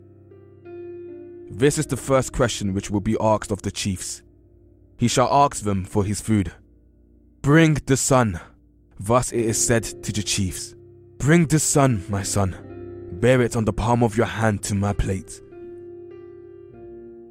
So I got caught up in a Twitter thread (as one does) discussing the dialect of a narrator in the overdub of an online video.